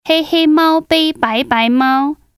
Record yourself reading it, then listen to how the speakers pronounce it.